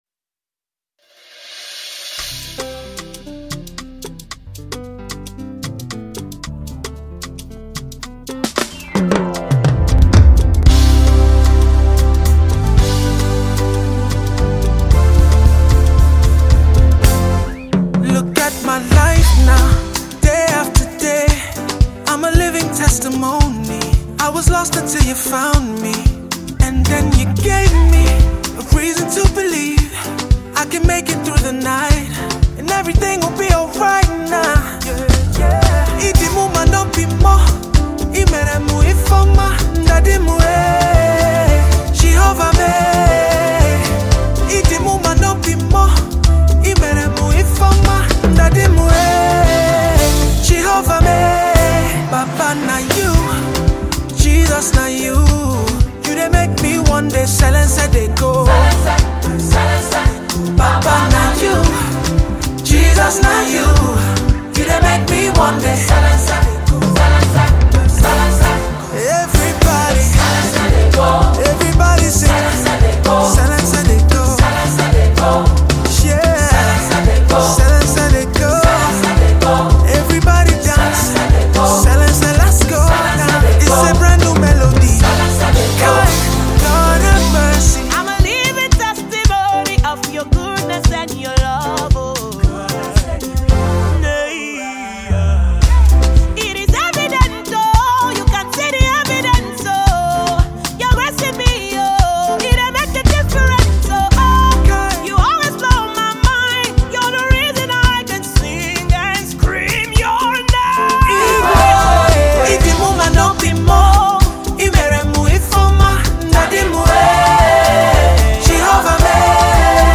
gospel vocalist